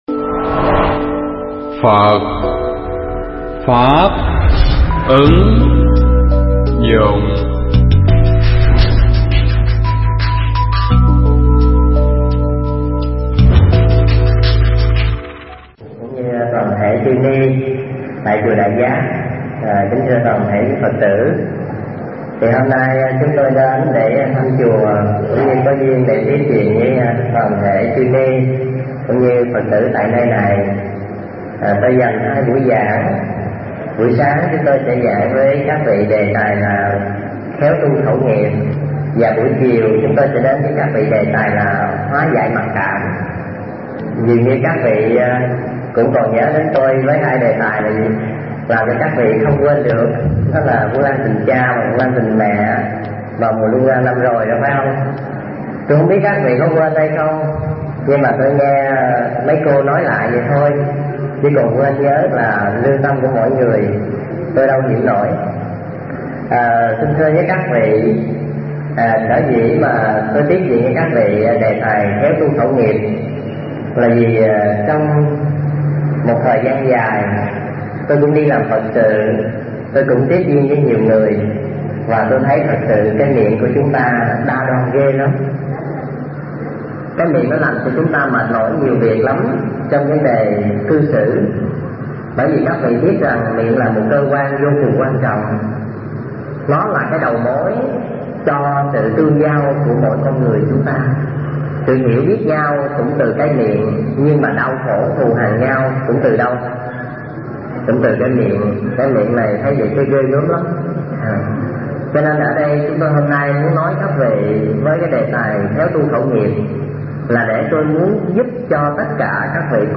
pháp thoại
thuyết giảng tại chùa Đại Giác